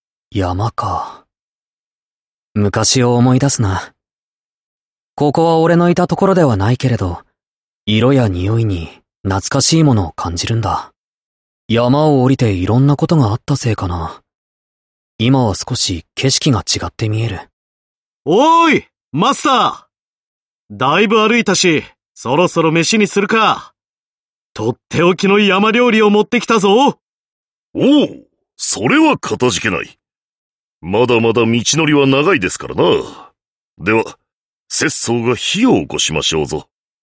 声优 小林裕介&浪川大辅&稻田彻